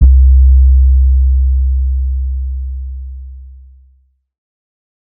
808s / 808 (18).wav